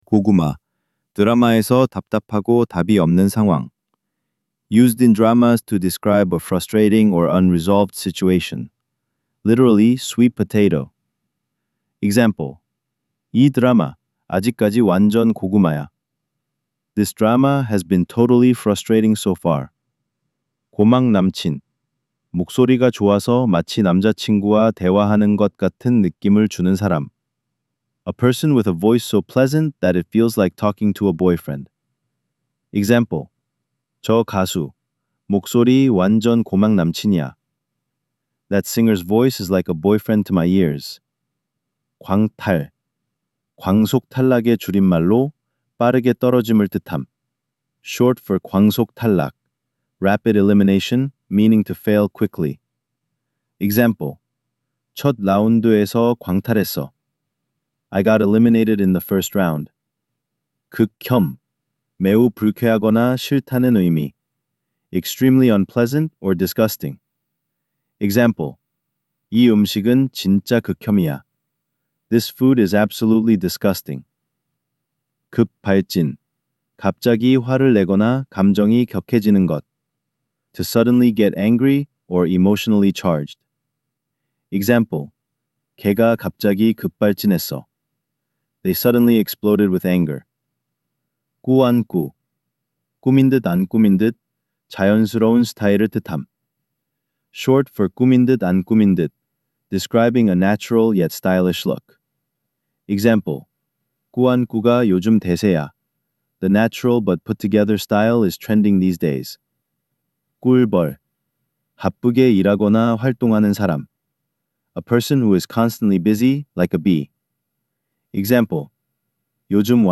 3) Korean Slang Decoded: Vol. 03 – Audio Narration